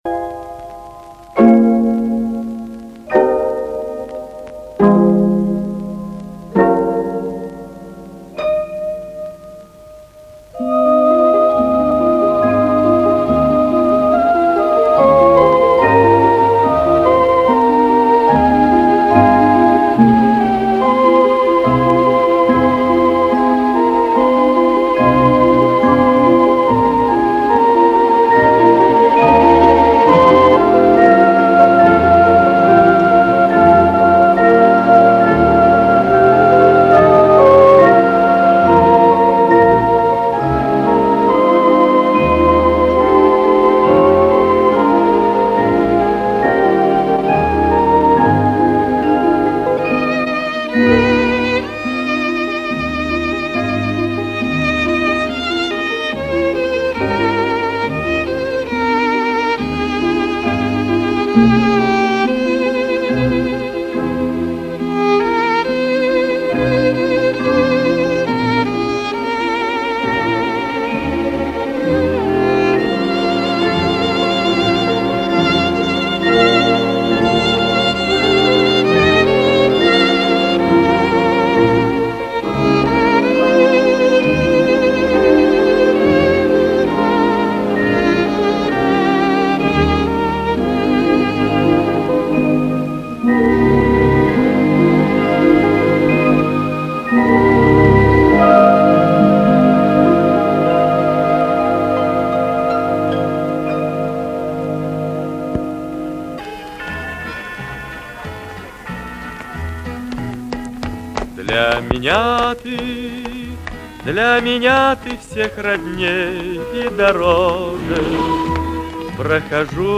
Вот только качество,к сожалению, позволяет желать лучшего.